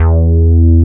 69.05 BASS.wav